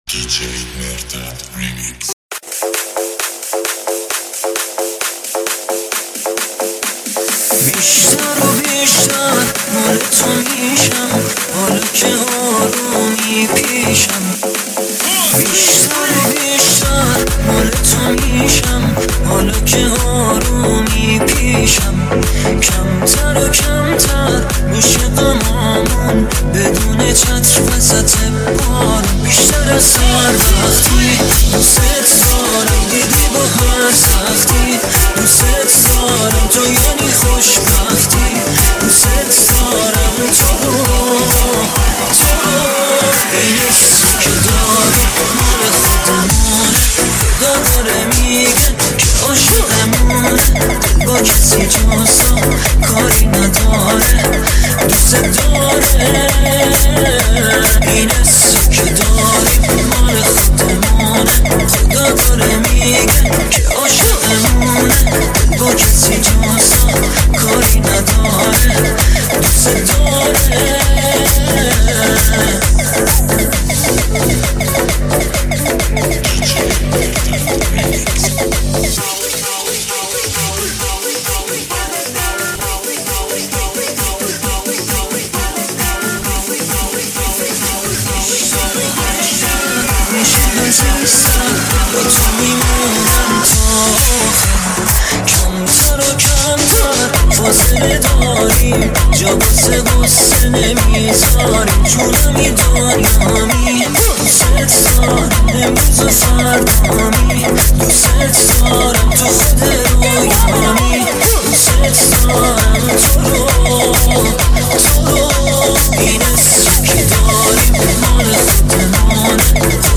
ریمیکس